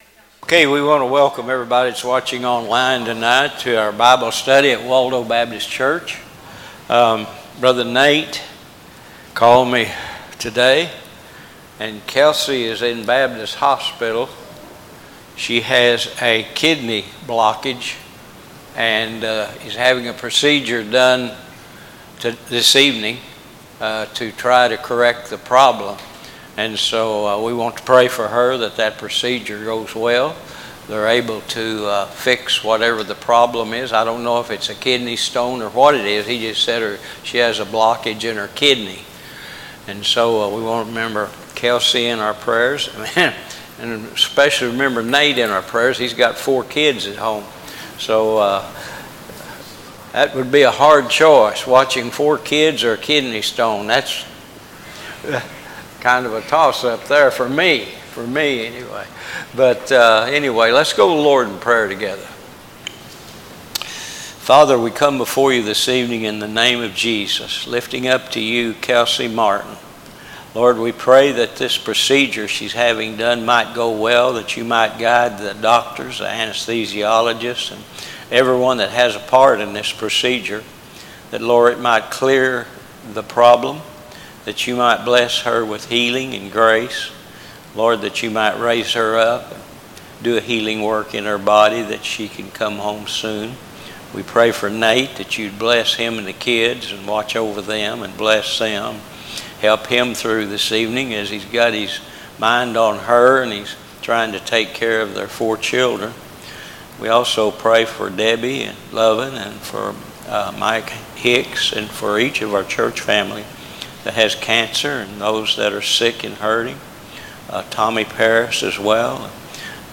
Wednesday Evening Bible Studies - 6:30pm